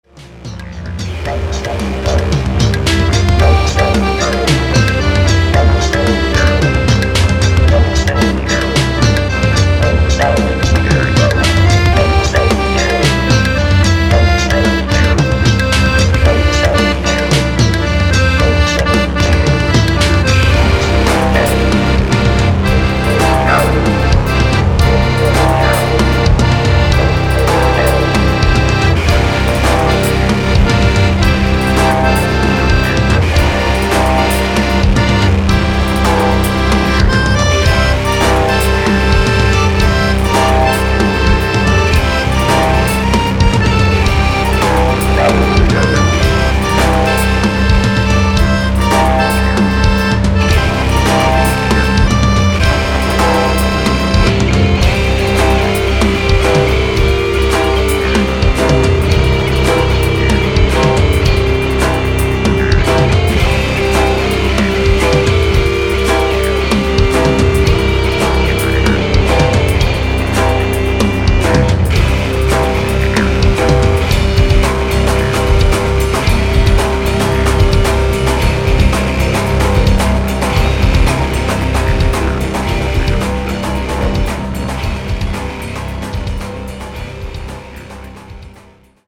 (instr.)